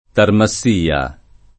Tarmassia [ tarma SS& a ] top. (Ven.)